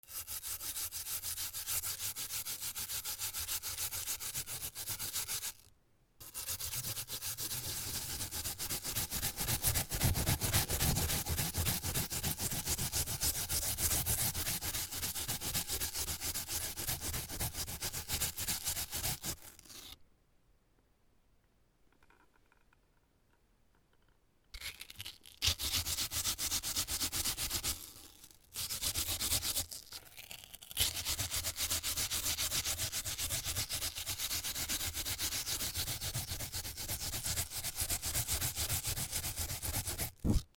山芋をする XY